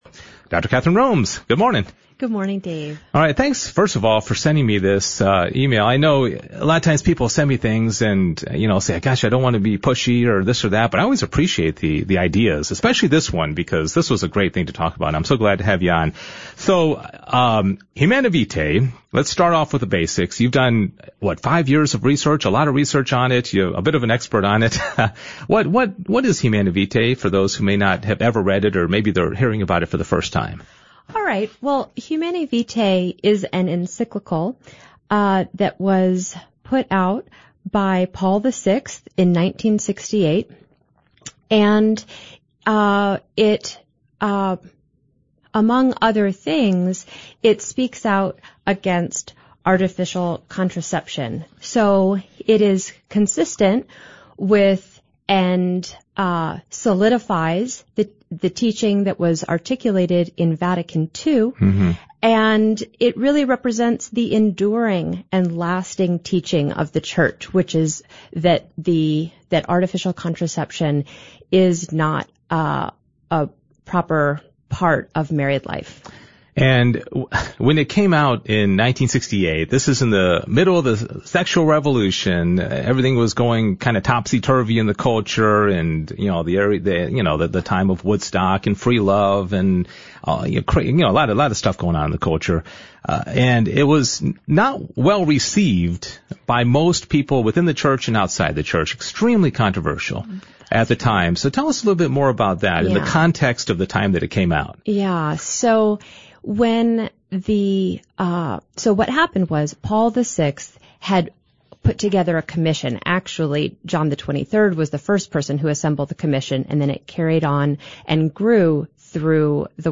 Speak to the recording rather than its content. I had the privilege of being on the radio this morning.